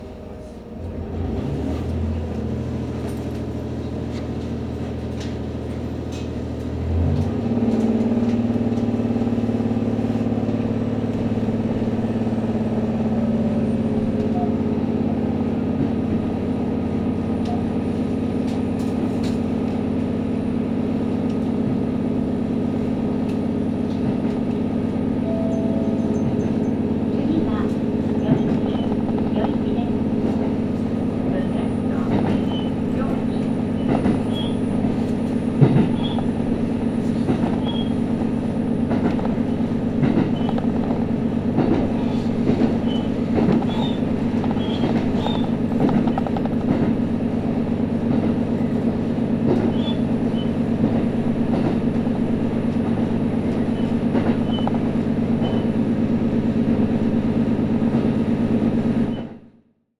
函館本線の音の旅｜仁木駅発車 H100系単行普通列車・余市方面の夜の走行音 | 旅行遂行士による乗り降り旅
函館本線・仁木駅を発車するH100系単行普通列車（小樽行き）の夜の走行音を収録。三月の静かな夜、少人数の車内と果樹園の広がる平野を進むディーゼル音と規則正しいジョイント音を楽しめる音鉄ショート版。